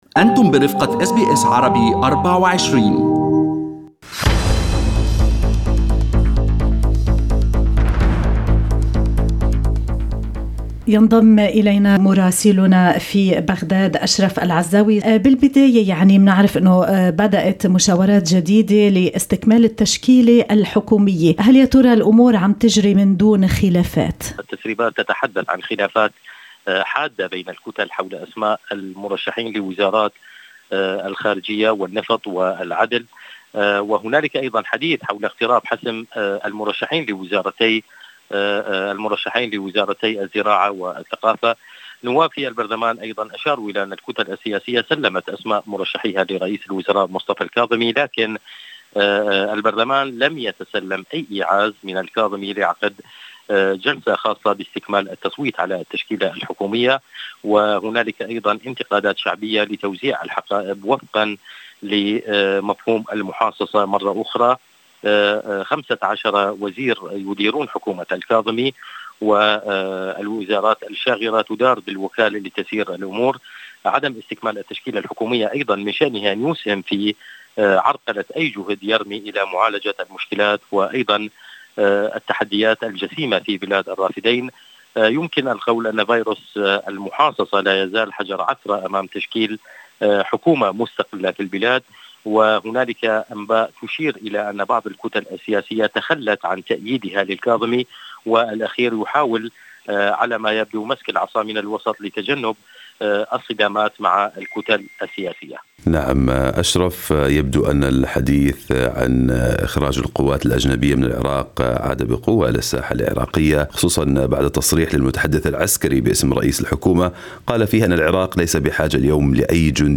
التقرير الصوتي